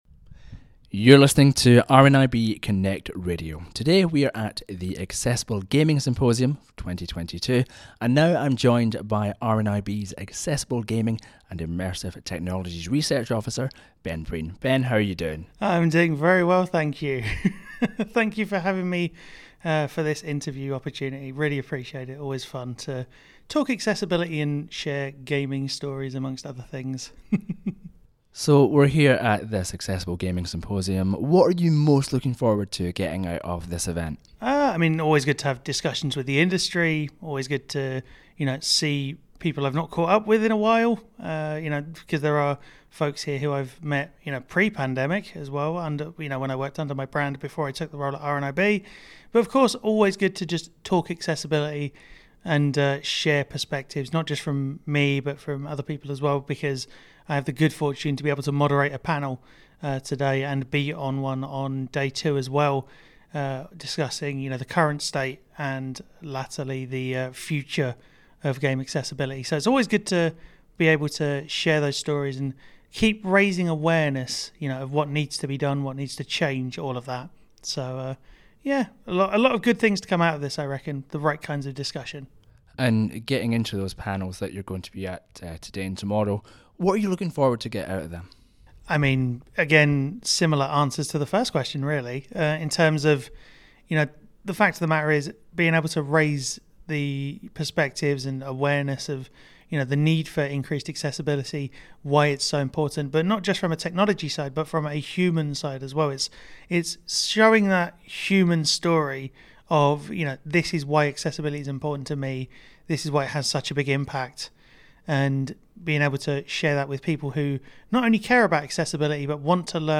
Accessible Gaming Symposium 2022 Interview